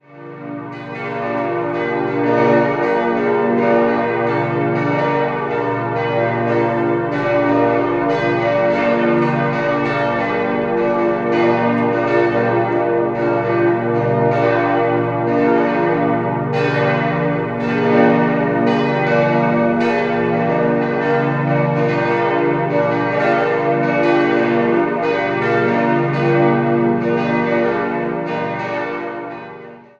Der Altarraum wurde 1990 durch Paul Brandenburg neu gestaltet. 4-stimmiges Salve-Regina-Geläute: b°-d'-f'-g' Alle Glocken wurden 1957 von Rudolf Perner in Passau gegossen und wiegen zusammen 6.564 kg.